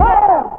Vox [ Bonk ].wav